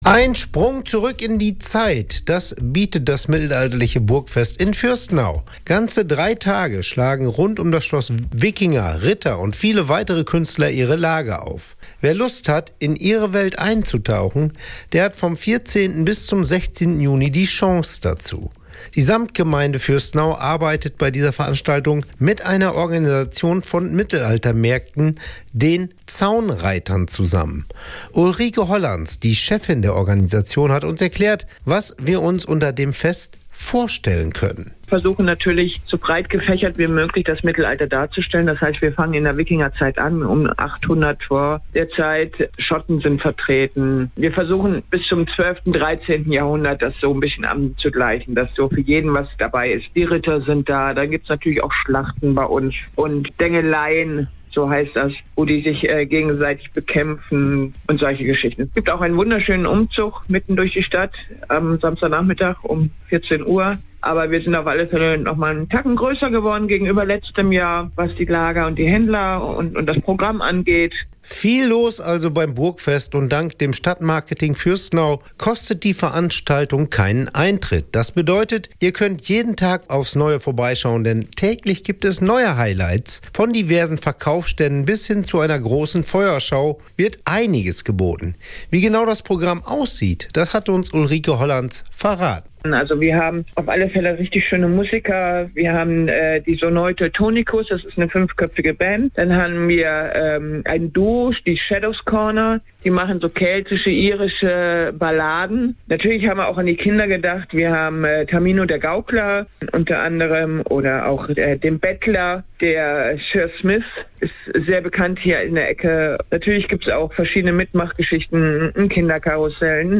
os-radio-beitrag-mittelaltermarkt.mp3